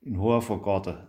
hochdeutsch Gehlbergersch
Hafergarten  Hoafergarde